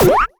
sci-fi_weapon_laser_small_fun_01.wav